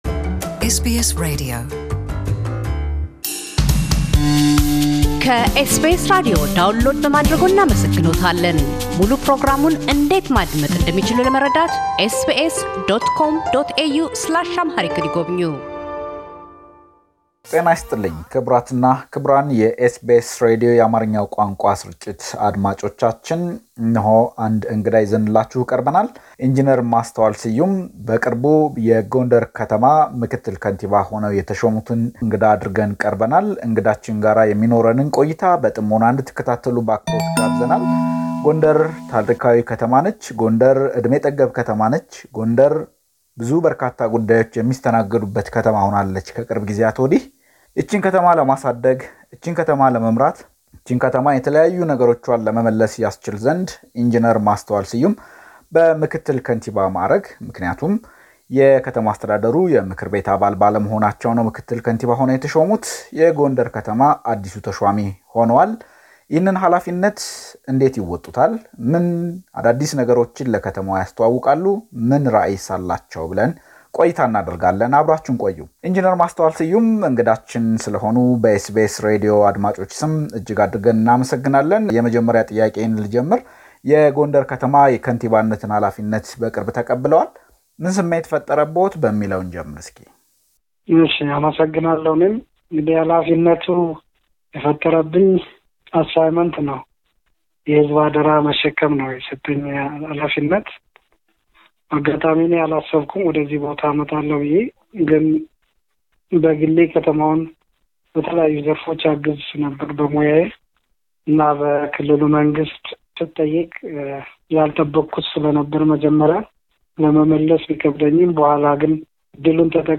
ኢንጂነር ማስተዋል ስዩም - የጎንደር ምክትል ከንቲባ፤ በቅርቡ እንደምን የከንቲባነት ኃላፊነትን እንደተቀበሉ፣ ለጎንደር ከተማ አልላቸው ትልሞችና ራዕይ ይናገራሉ። በባሕር ማዶ ለሚኖሩ ኢትዮጵያውያንና ትውልደ ኢትዮጵያውያንም ጥሪ ያቀርባሉ።